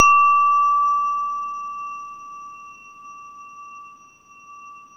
WHINE  D4 -L.wav